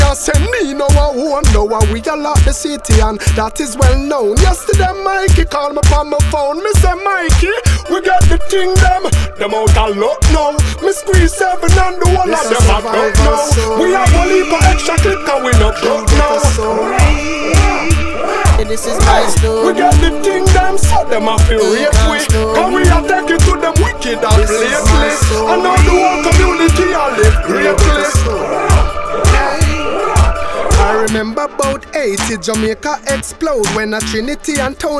Жанр: Регги
Reggae, Modern Dancehall